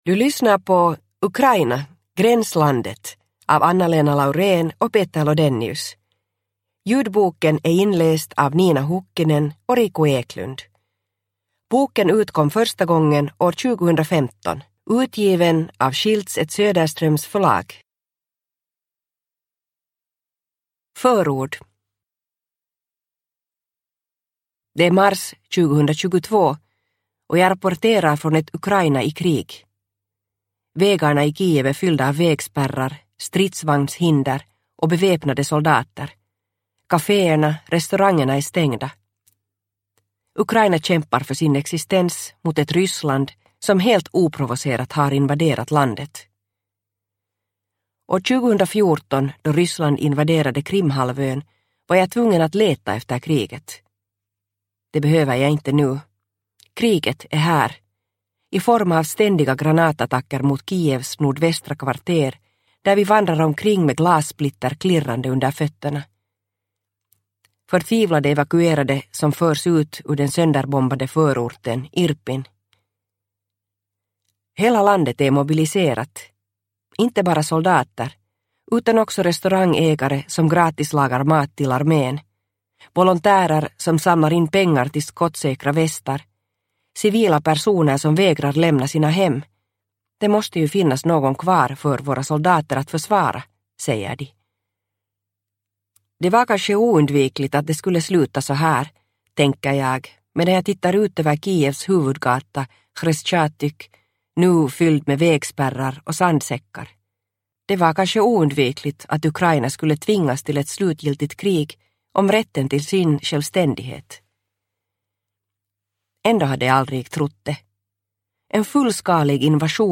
Ukraina - gränslandet – Ljudbok – Laddas ner